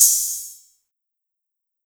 Metro Bright Open Hat.wav